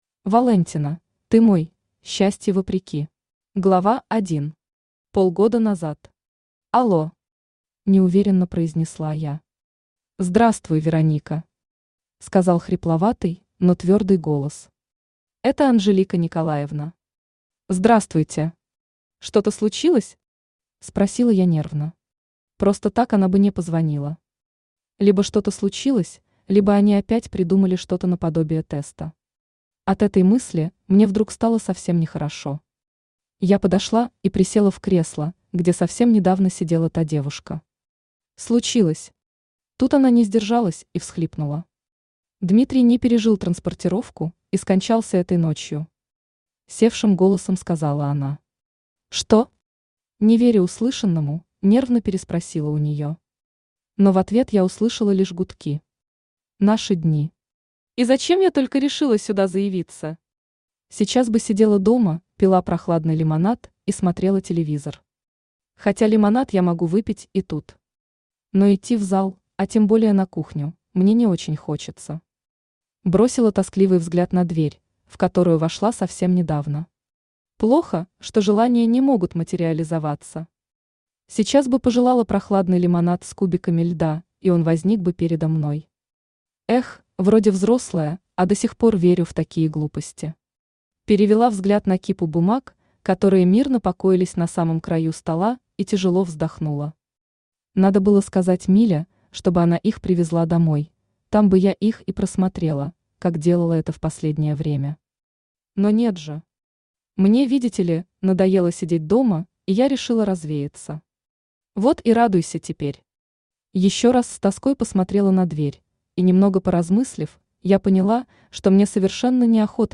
Аудиокнига Ты моя! Противостояние | Библиотека аудиокниг
Противостояние Автор Walentina Читает аудиокнигу Авточтец ЛитРес.